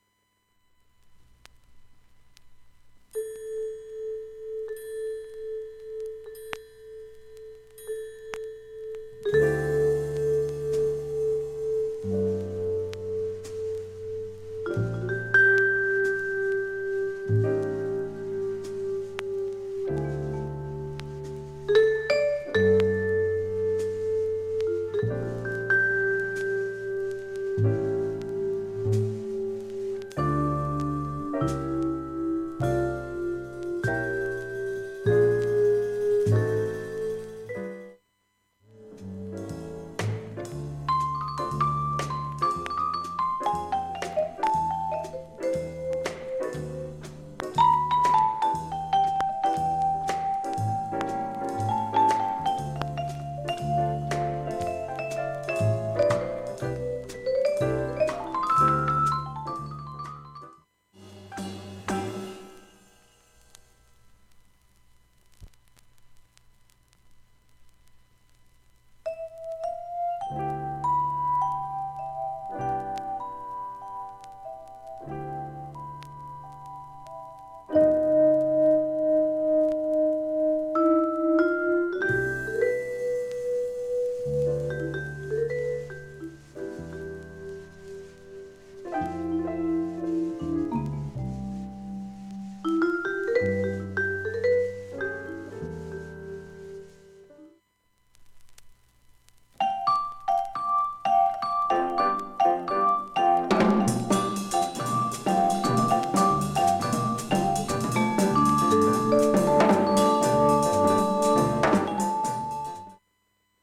ほか静かな部でも音質良好です。
大半は音質かなり良好です。
クールなヴィブラフォンがパーカッションと共に 疾走するラテンジャズ